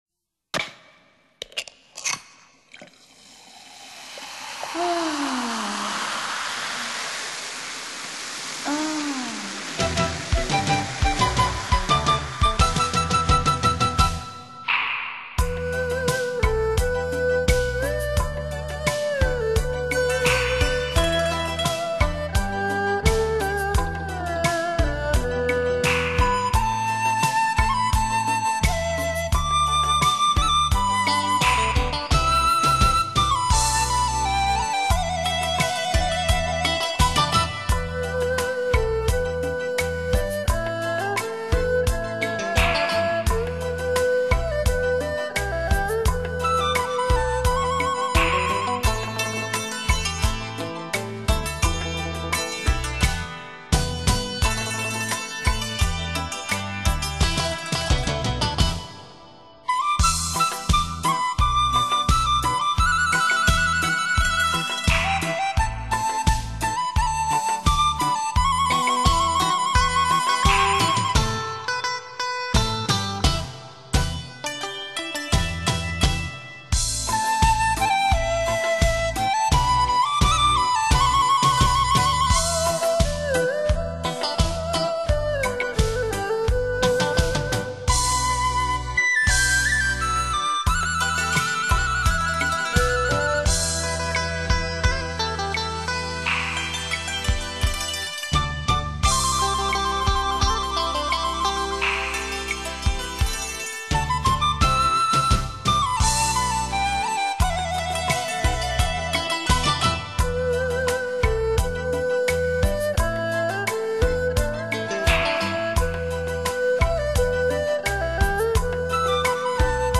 华乐